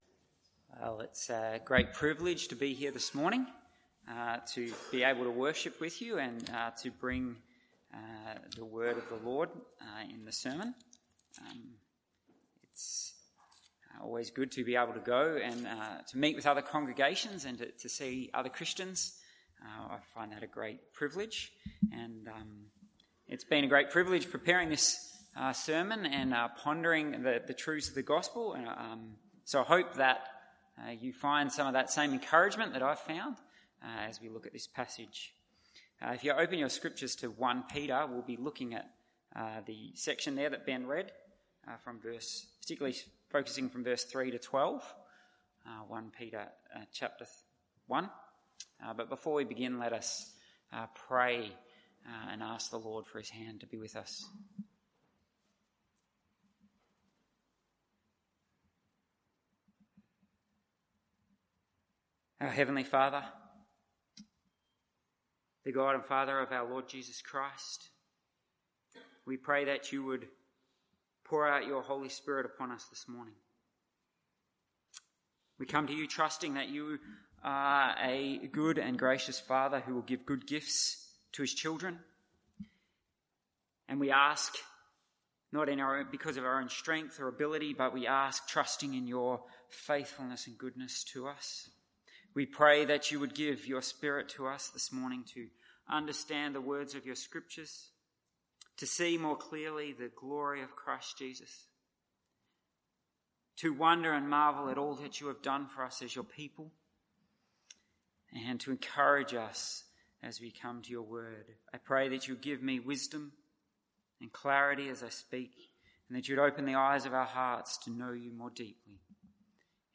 MORNING SERVICE 1 Peter 1:1-13…